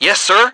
H_soldier3_10.wav